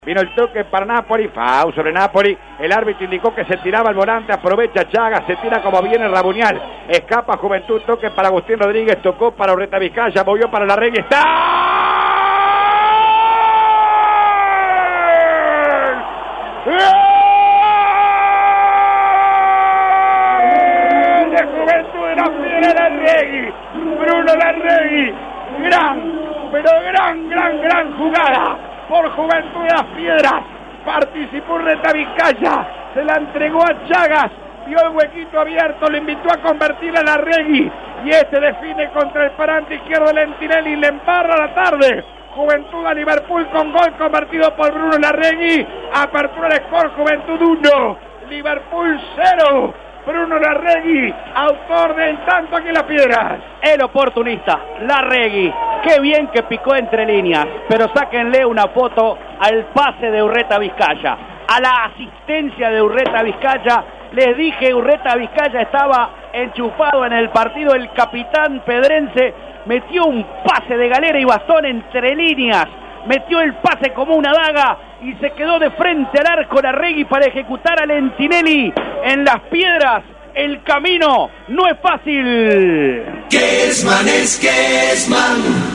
GOL RELATADO